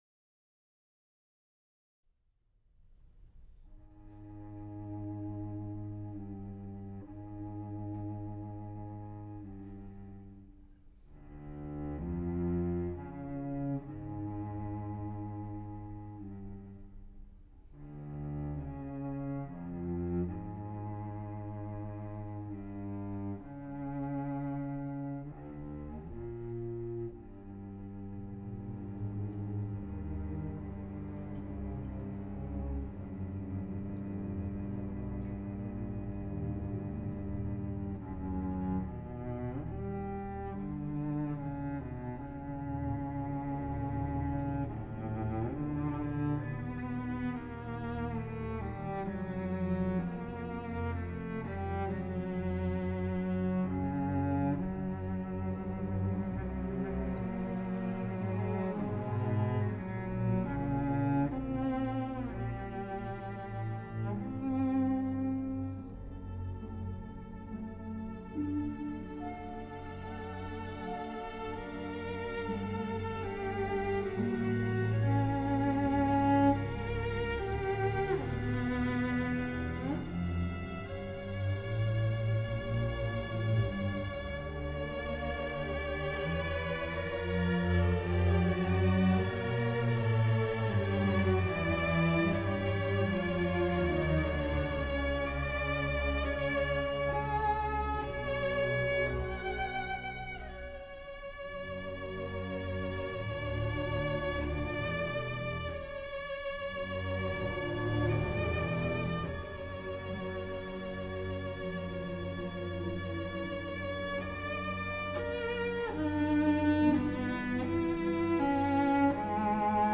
элегическая первая часть